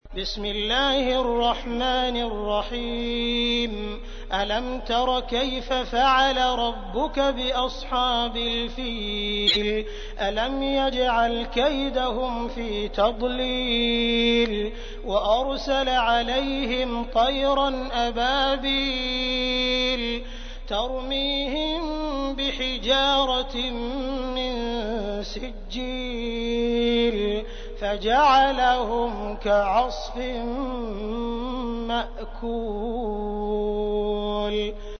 تحميل : 105. سورة الفيل / القارئ عبد الرحمن السديس / القرآن الكريم / موقع يا حسين